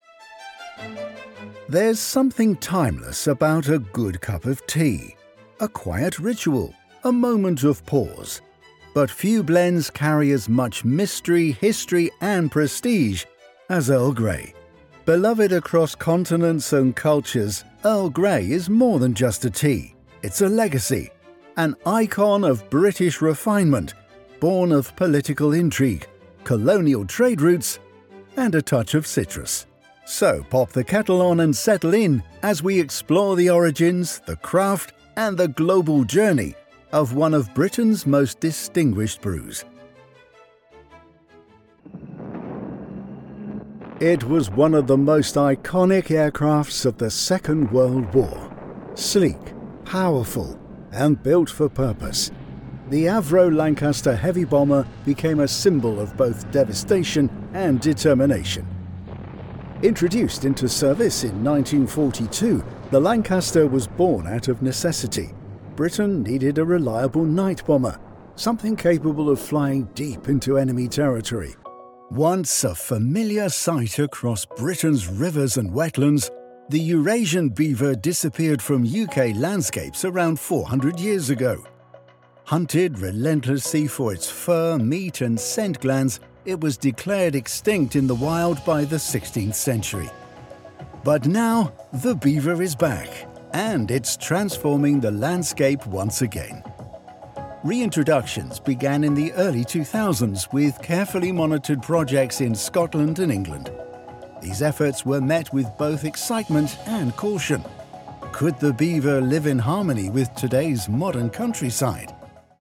Documentaries
I have a natural accent free (RP) style voice which is flexible and can adapt to most voiceover projects.
Rode NT1a Condensor Mic, Mac Mini m4, Adobe Audition CC, Scarlett Solo Interface, Tannoy 405 Studio monitors, Sennheiser HD 280 Pro monitoring headphones.
Deep